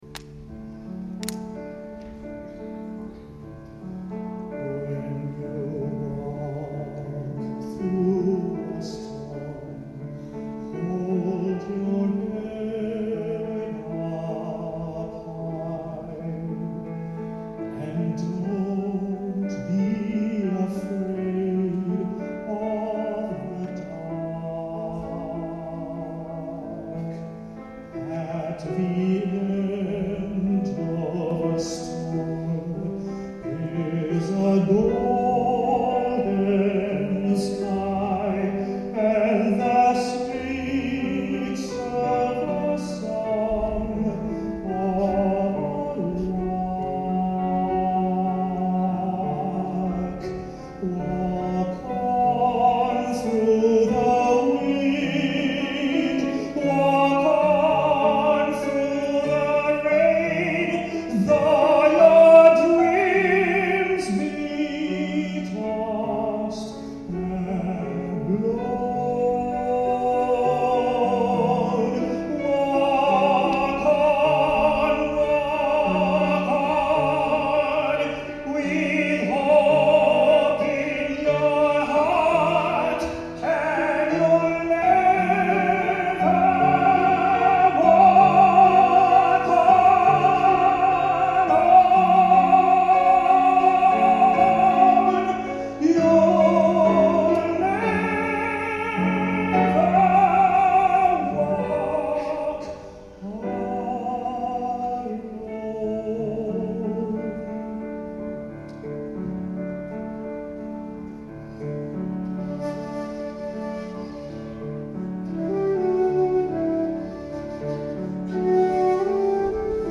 These songs are best heard on headphones to hear the direction of chatter, laughter and so on.
accompanied on piano